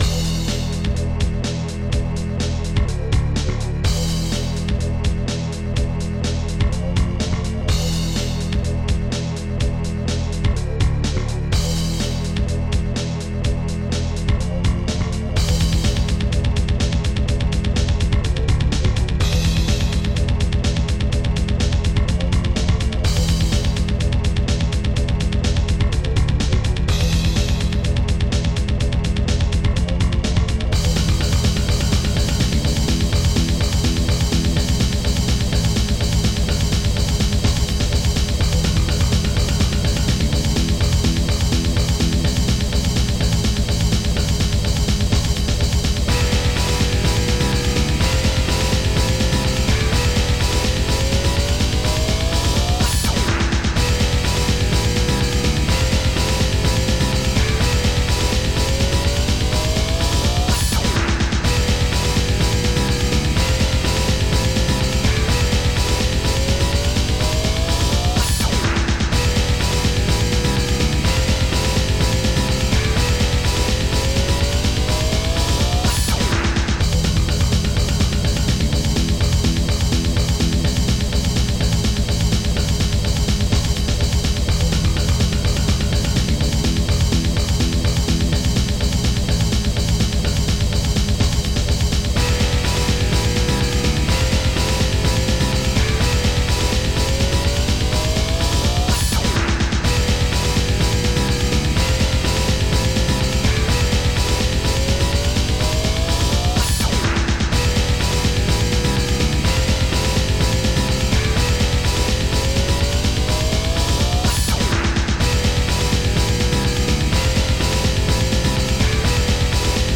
FastTracker Module
2 channels